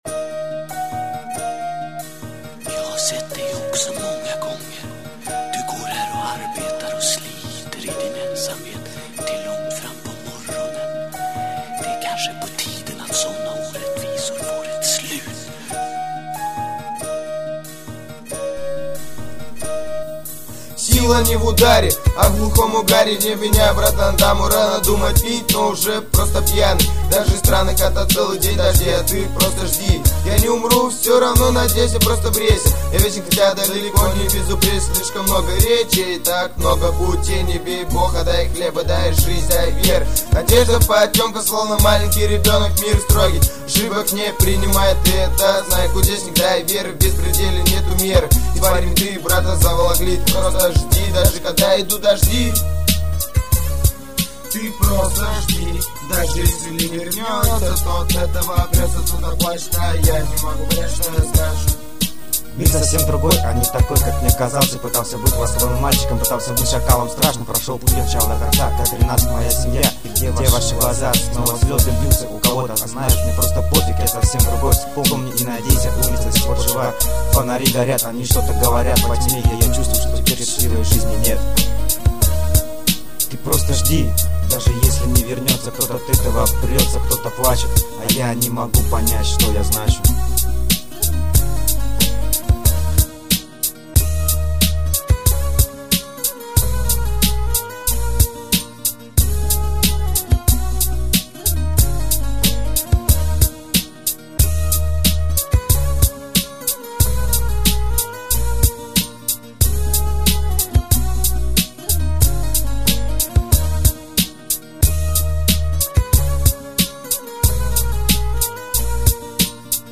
молодая рэп группа
Трэки:, 2005\21 Рэп